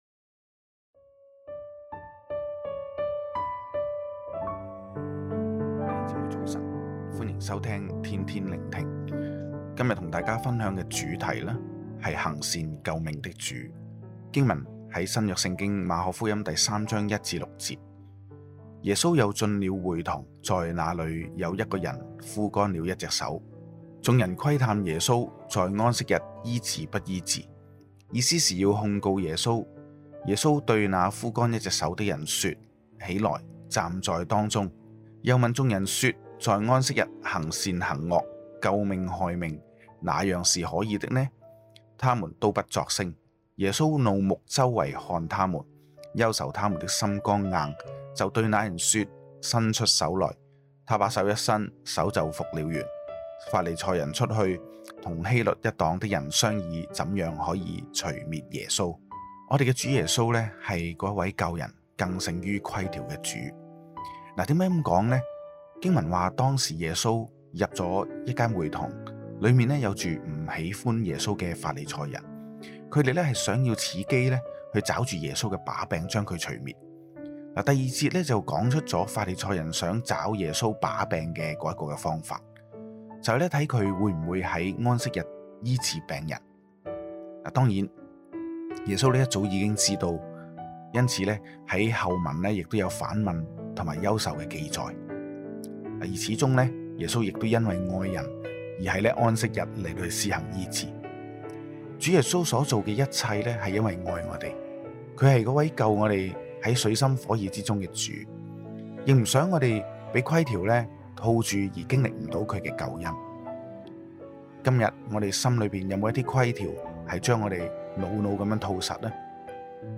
普通话录音连结🔈